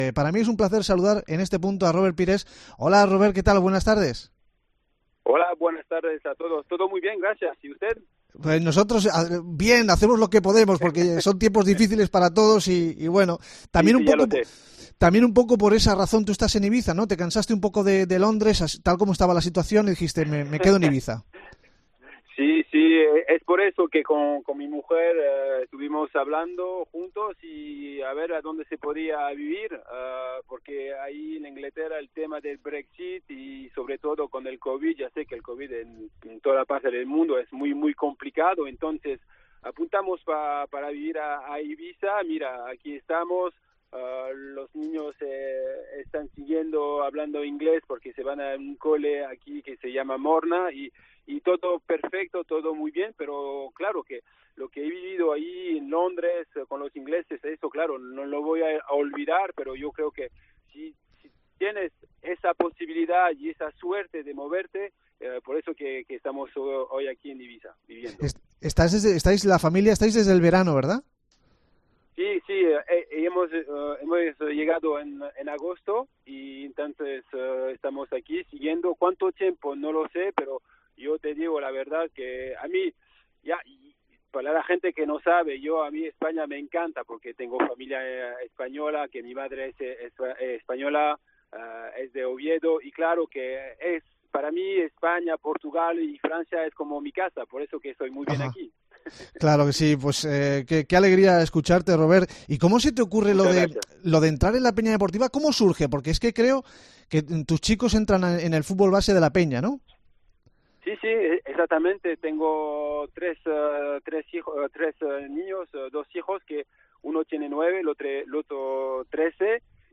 Entrevista con Robert Pires